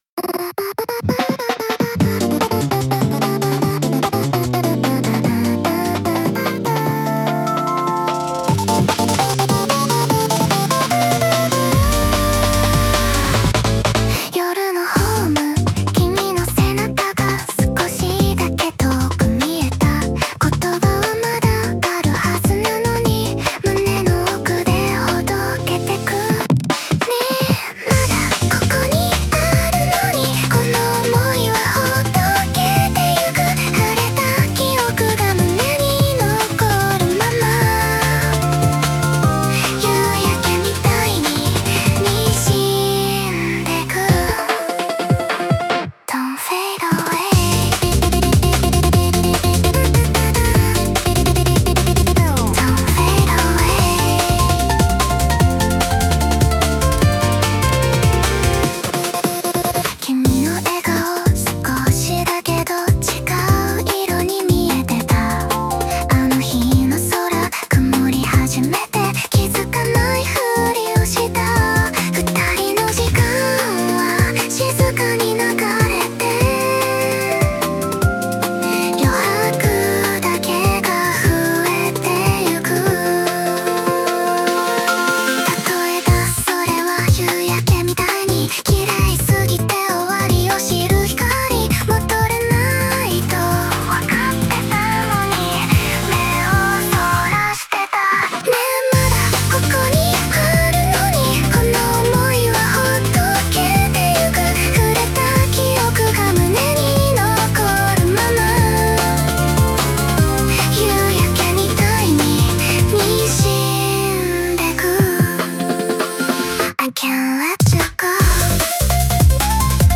女性ボーカル
イメージ：ハイパー・ポップ,サイケデリック・ポップ,ボサノバ,ブレイク,女性ボーカル,2ステップ,かっこかわいい